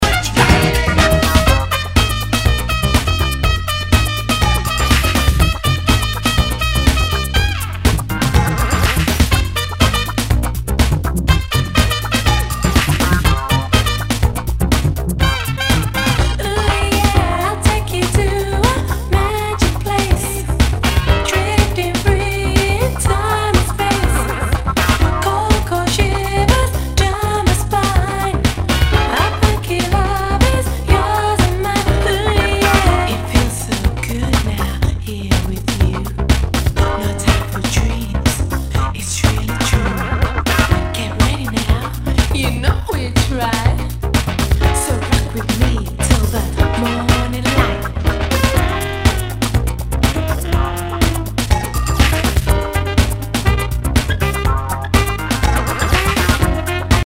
HOUSE/TECHNO/ELECTRO
ナイス！ヴォーカル・ハウス・クラシック！